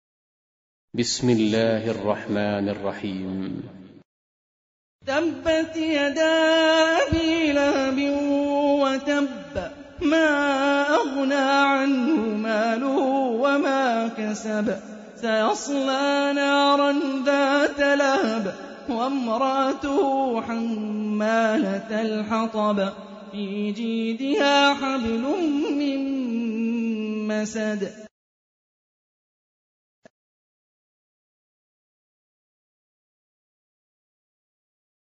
Tarteel Recitation
حفص عن عاصم Hafs for Assem